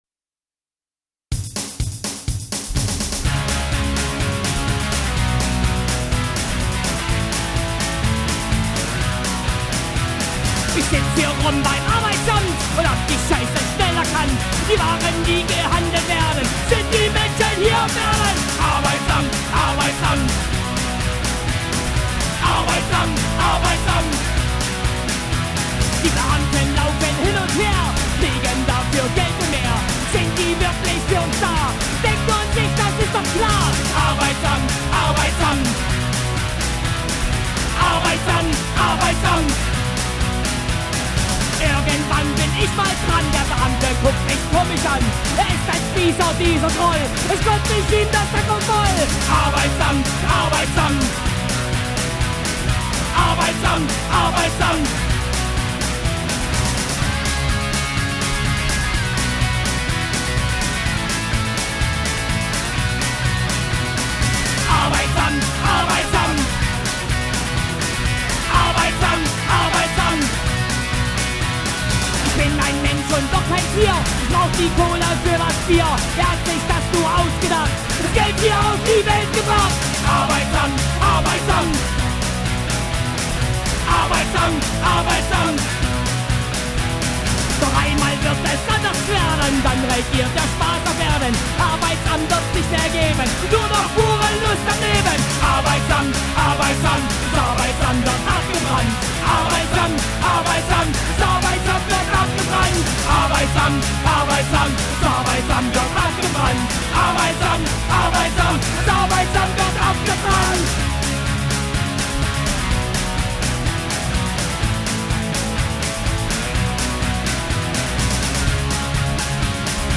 Anarcho Punk, DeutschPunk